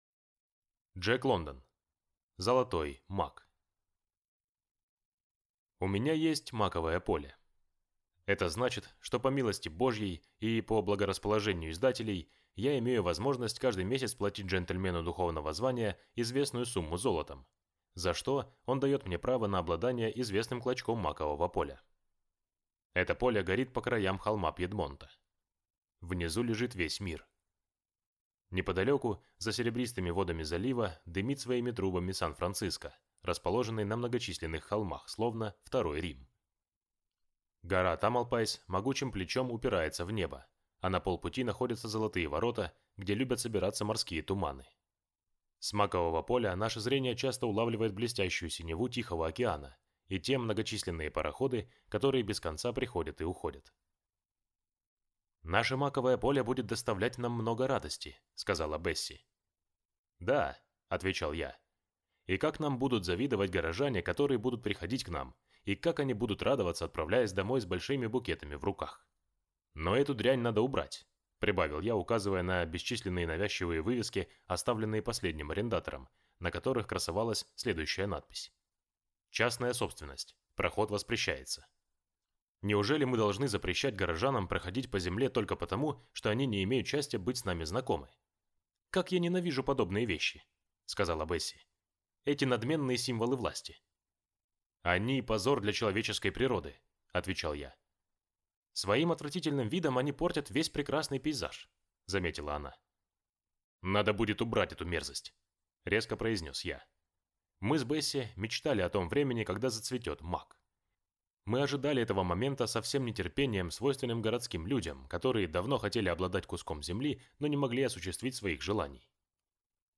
Аудиокнига Золотой мак | Библиотека аудиокниг
Прослушать и бесплатно скачать фрагмент аудиокниги